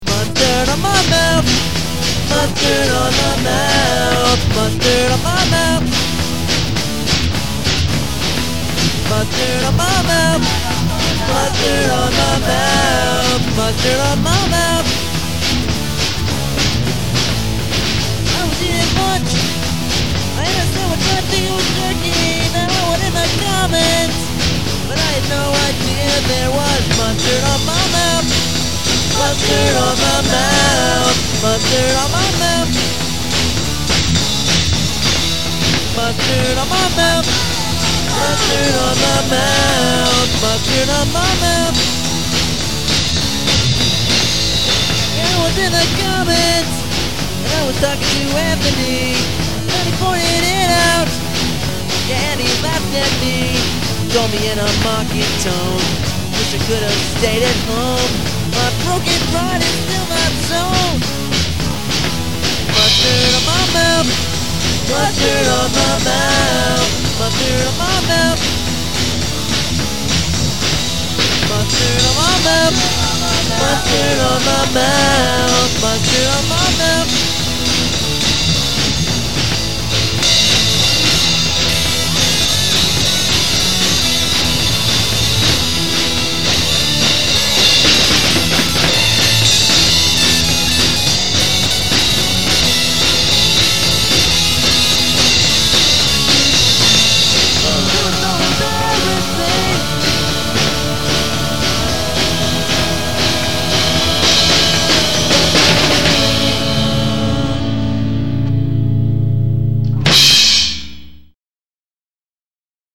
The first full length from a budding pseudo-punk band.
We still only had one mic for the drums.